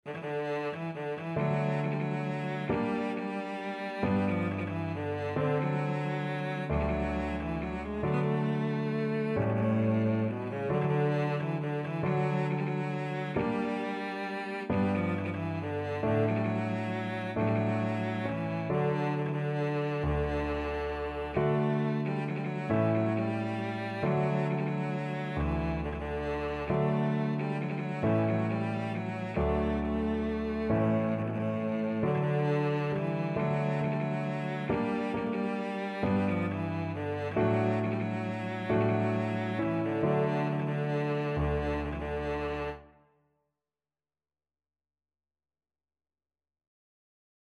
One in a bar .=45
A3-A4
3/4 (View more 3/4 Music)
Cello  (View more Easy Cello Music)